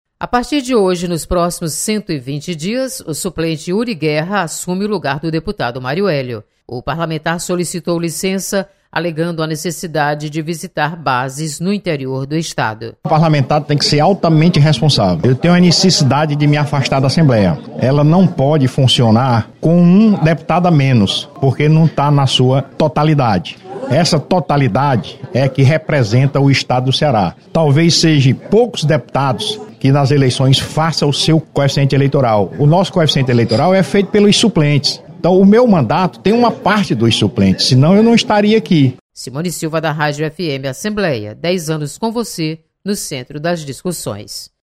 Você está aqui: Início Comunicação Rádio FM Assembleia Notícias Licença